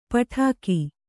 ♪ paṭhāki